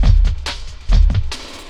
59 LOOP 02-L.wav